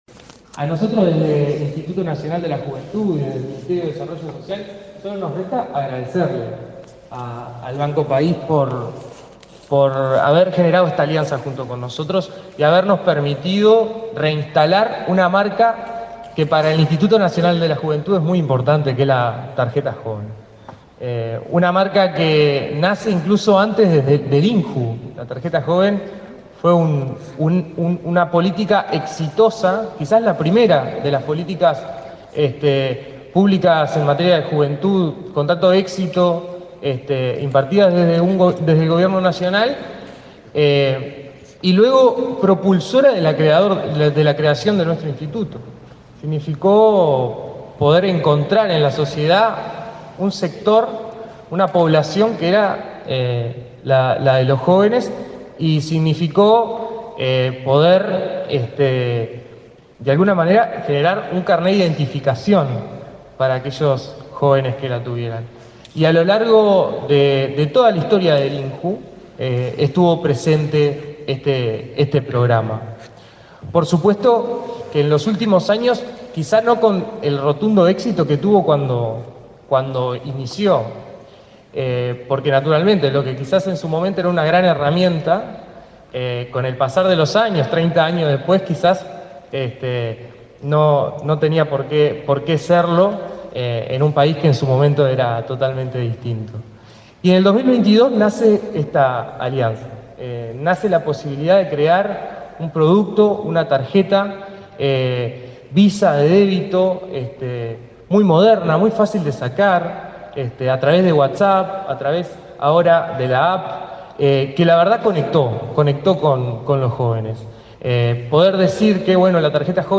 Palabras de autoridades en acto en INJU